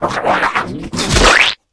spit.ogg